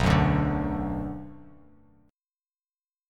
Bm Chord
Listen to Bm strummed